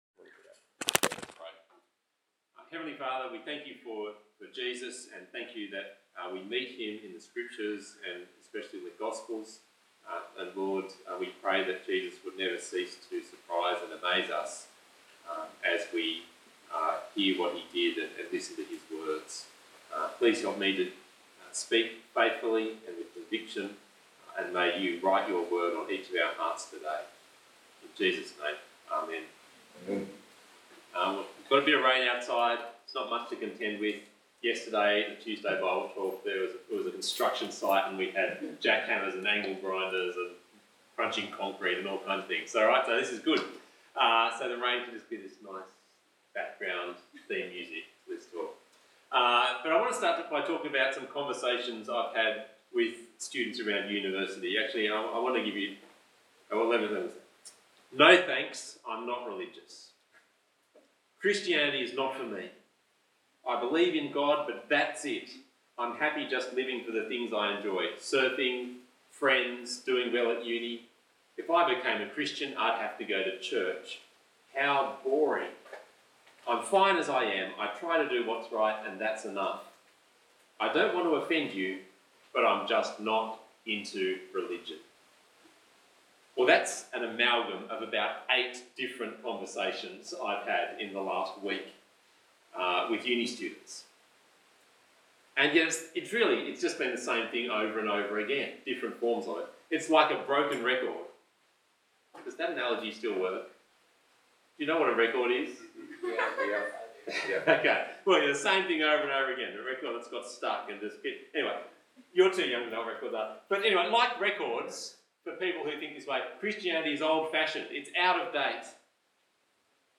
Passage: Matthew 9:9-17 Talk Type: Bible Talk